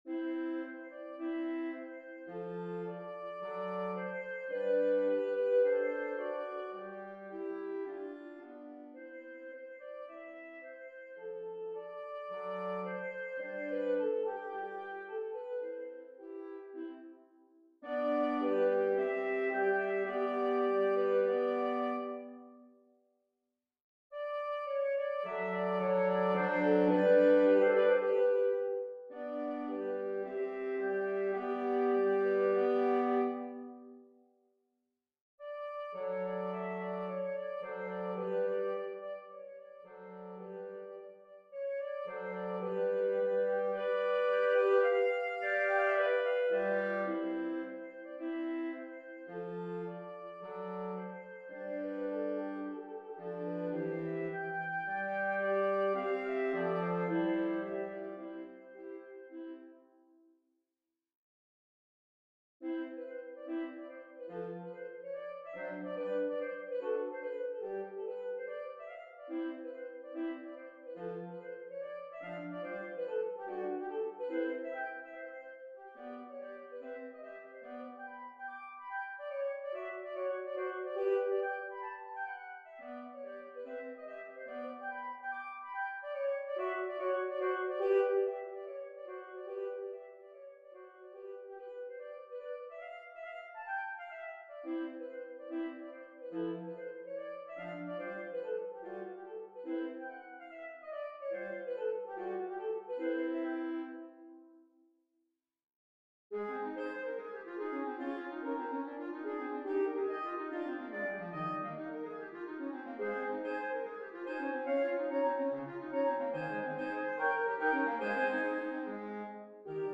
Ensemble: Saxophone Trio SAT or SAB